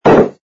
sfx_put_down_bottle04.wav